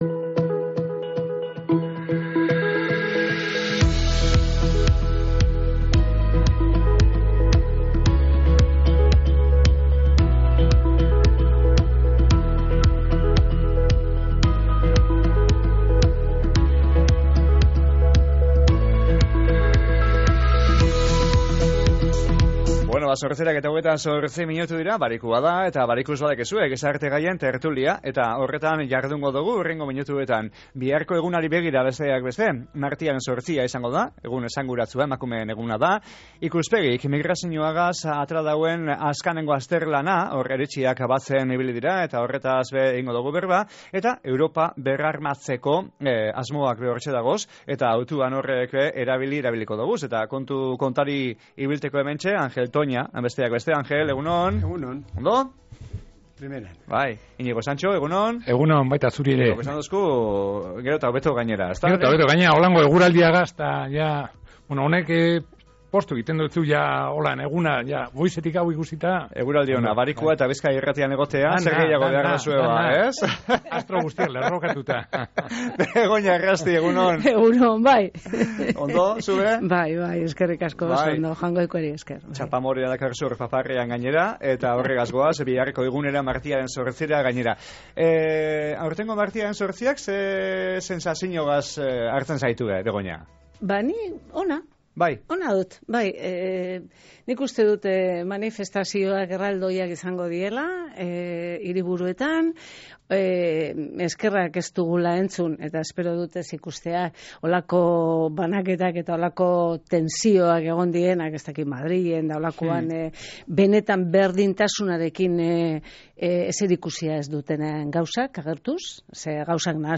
Martiaren 8a eta Ikuspegiren txostena gaurko gizarte gaien tertulian | Bizkaia Irratia
GIZARTE-GAIEN-TERTULIA-1.mp3